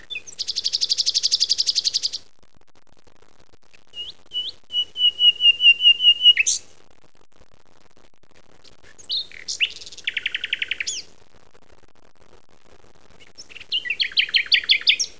Lief nachtegaaltje.
Nachtegaal2_Luscinia-megarhynchos.wav